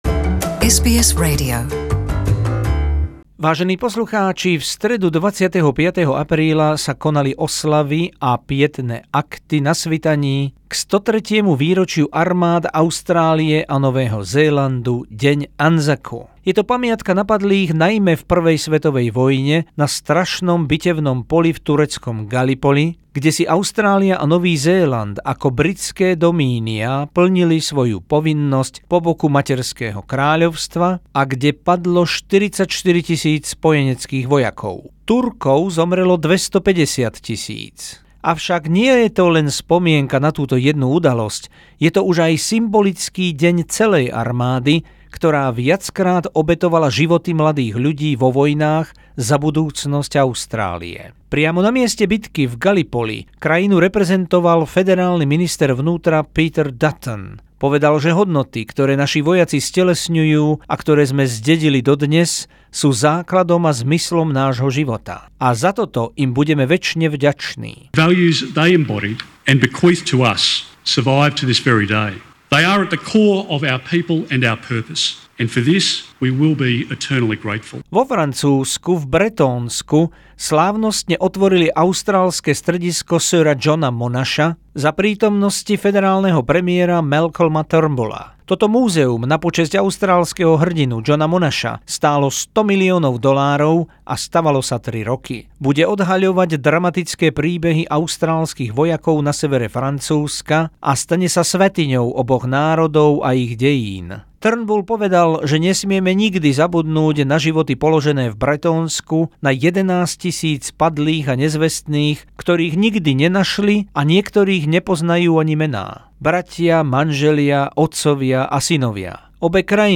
Oslava smelosti, hrdinstva a obetavosti vojakov Anzacu zo spravodajskej dielne SBS a úryvkami prejavov v Gallipoli, Villers-Bretonneux a Canberre